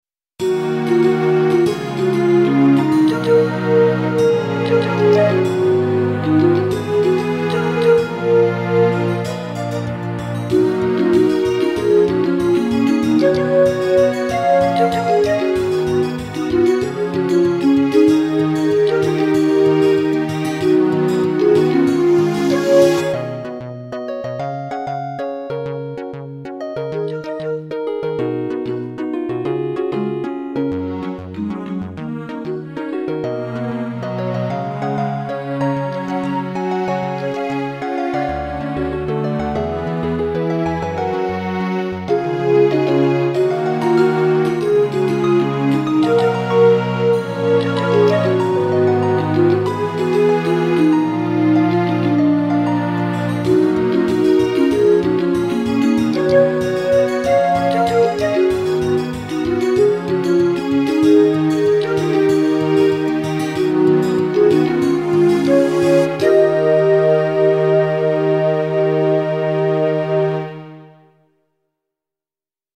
Game style music